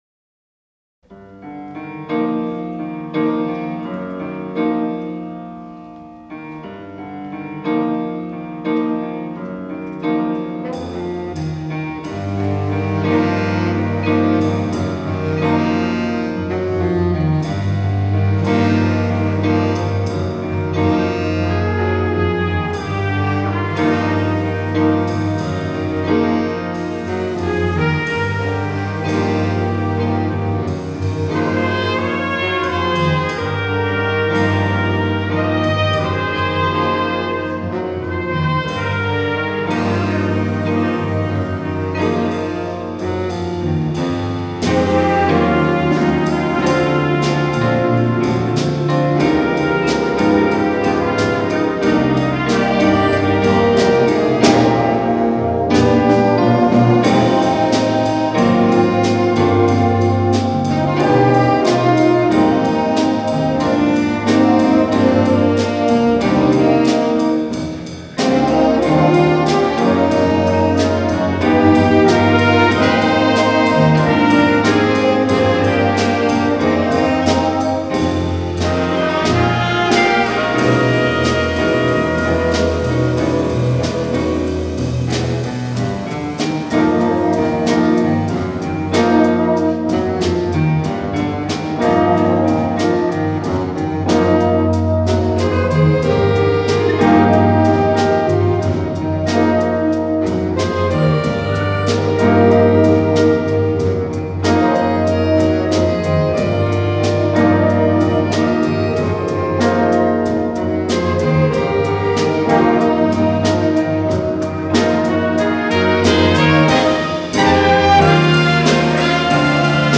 Jazz Concert 2023 Recordings